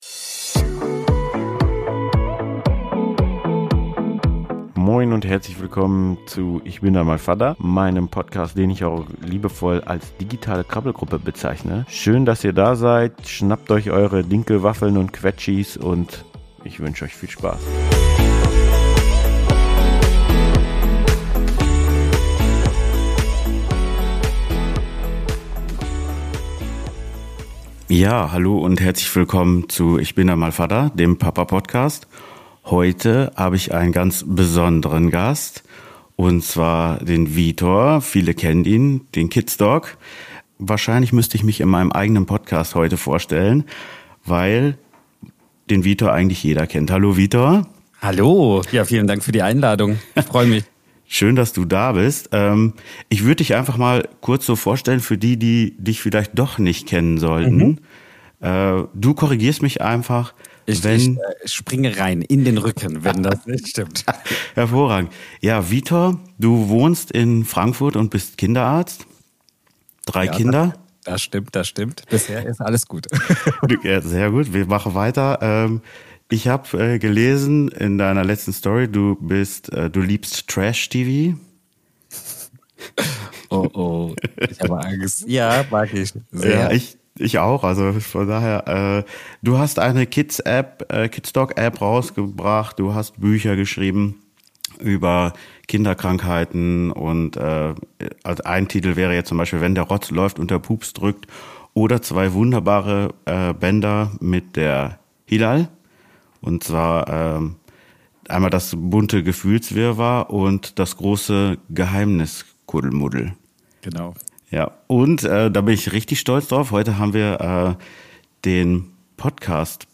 Auf diese Fragen gehen wir in einem wirklich sehr sympathischen und ehrlichen Gespräch ein und kommen dabei an vielen spannenden Themen vorbei.